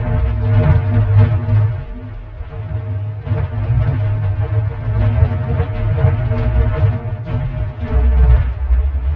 音乐锯》唱9
标签： 音乐 锯床 奇怪 奇怪 异形 爬行 恐怖 科幻 可怕
声道立体声